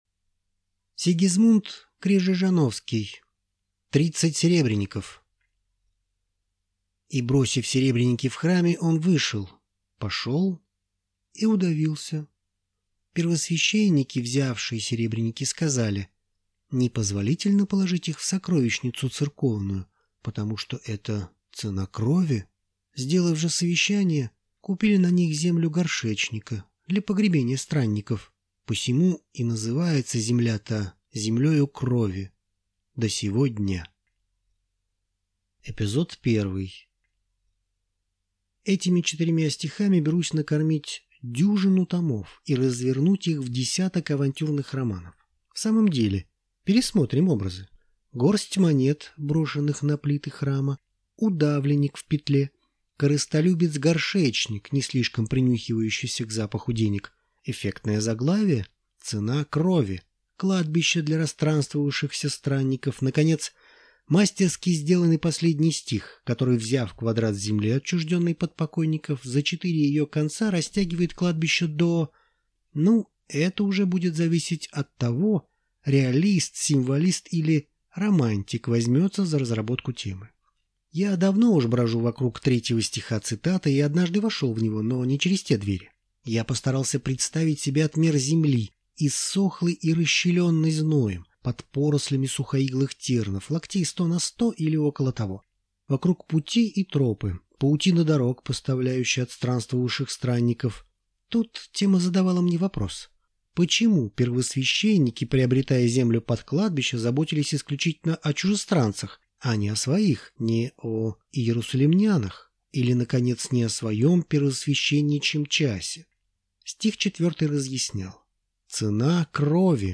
Аудиокнига Тридцать сребреников | Библиотека аудиокниг